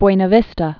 (bwānə vĭstə, bwĕnä vēstä)